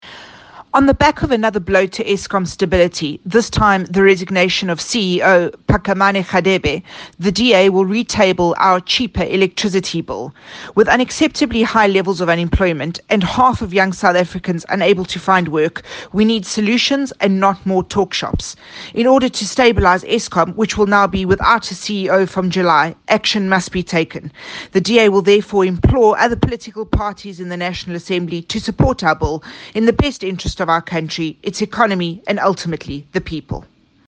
soundbite in English by Natasha Mazzone  MP.